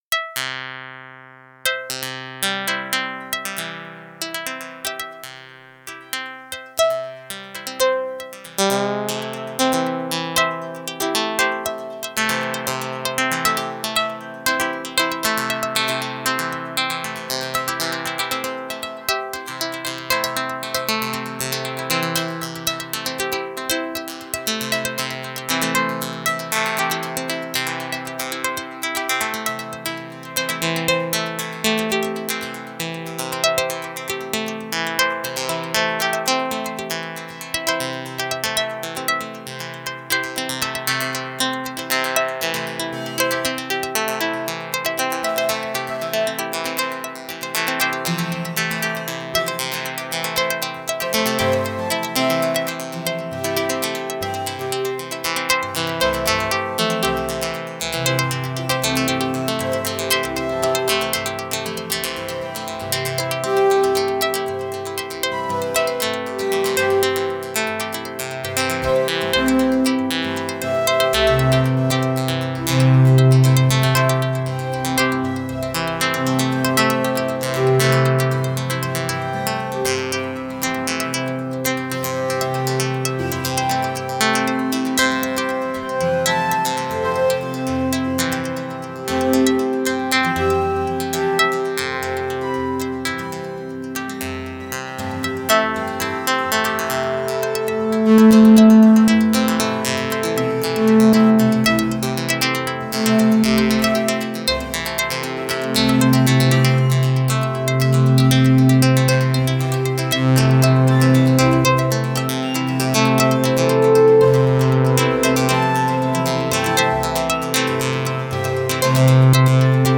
I decided to feed different synth sounds into a delay line that was then processed by the Karplus-Strong algorithm.